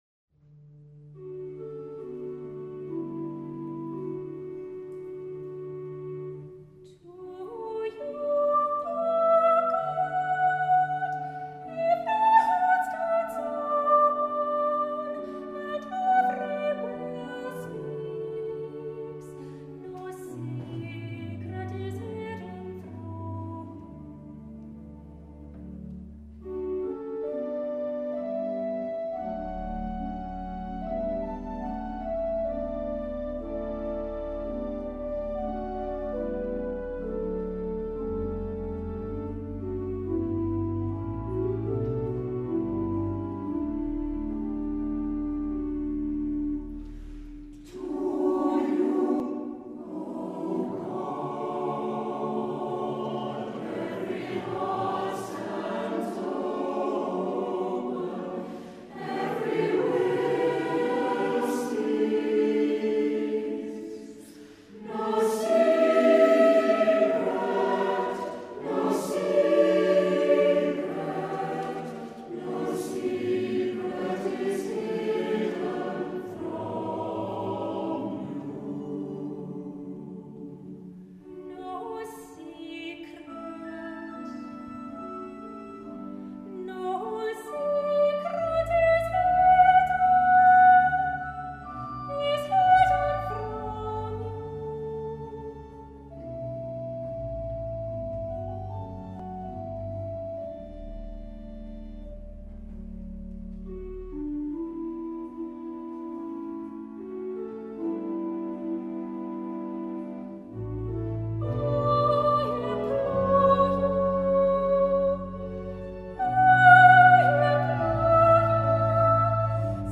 For Soprano Solo SATB Chorus, SATB Semi-Chorus and Organ.
anthem